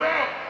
Southside Vox (23)_2.wav